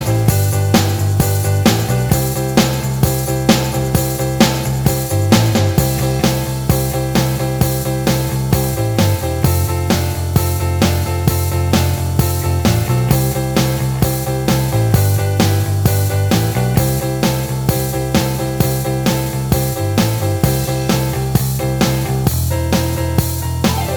Minus Guitars Rock 3:39 Buy £1.50